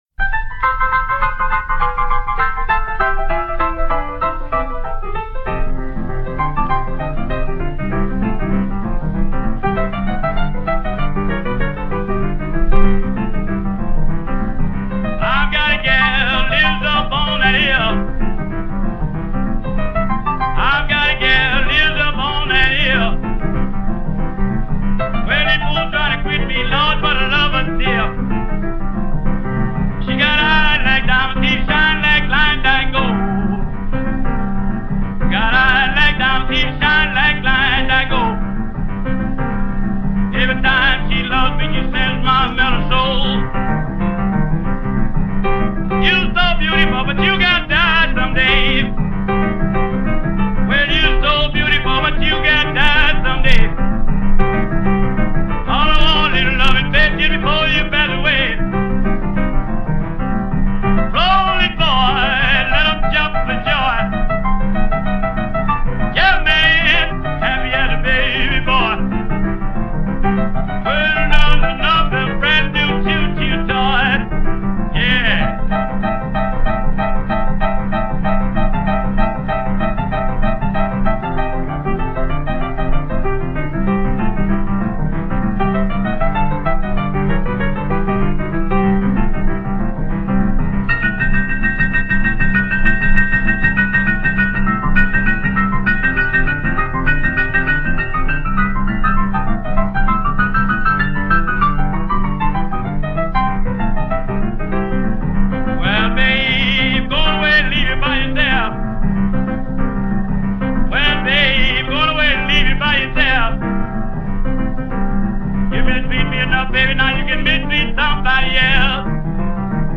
Jump, Boogie Woogie, Blues and Rhythm